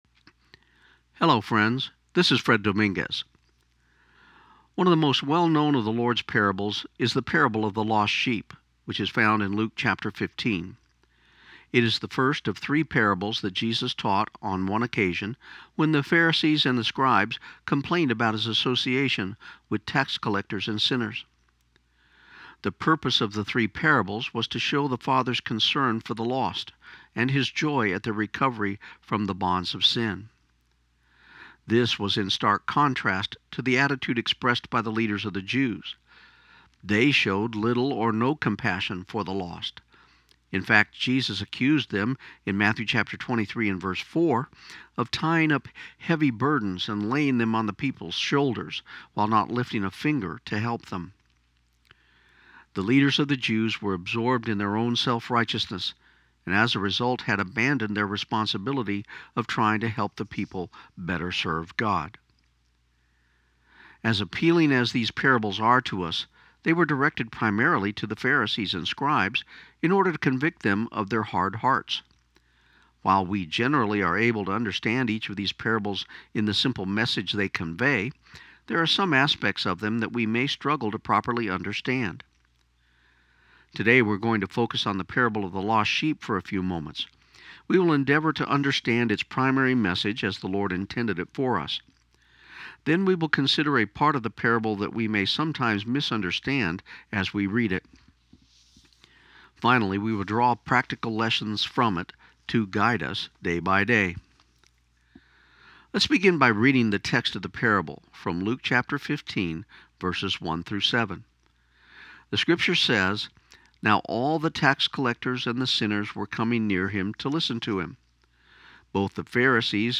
This program aired on KIUN 1400 AM in Pecos, TX on January 18, 2017.